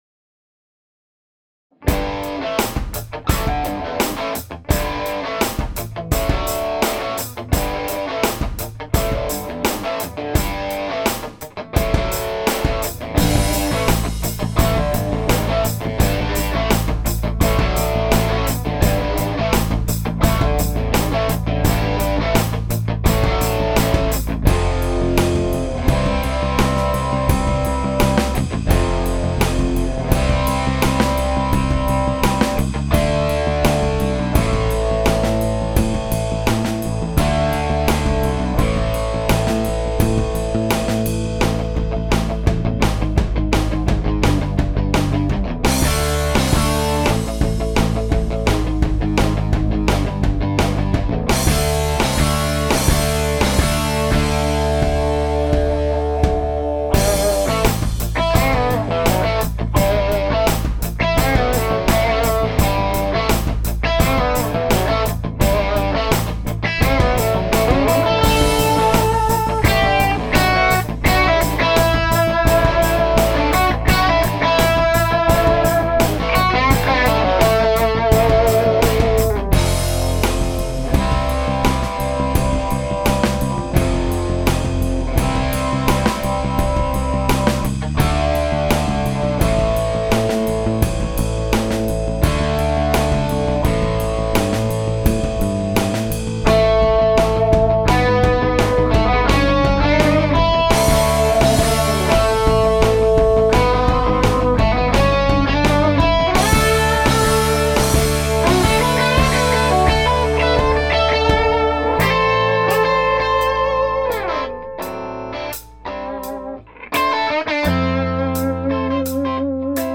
Unless otherwise illustrated, the MP3 tracks above are all recorded with the guitars ('98 Fender '57 RI USA Strat or '68 Gibson 335) straight into the Tweed Deluxe - with the exception of
(1960 Les Paul Jr. Doublecut)